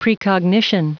Prononciation du mot precognition en anglais (fichier audio)
Prononciation du mot : precognition